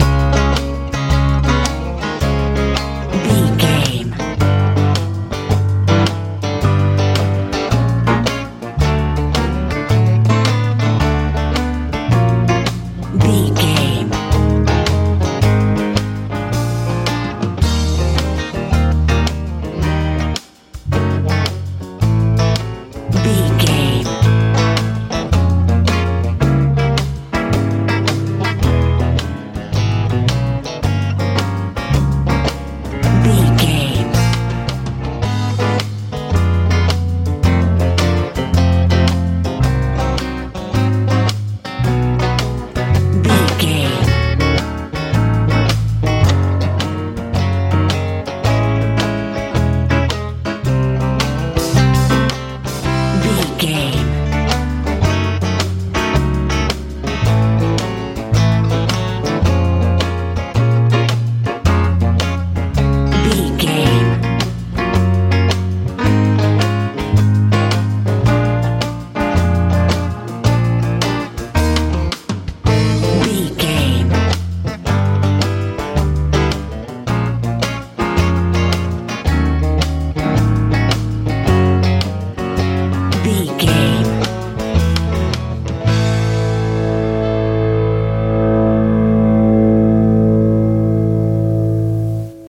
haystack country feel
Ionian/Major
C♯
light
piano
electric guitar
bass guitar
drums
romantic
sentimental
smooth
soft
soothing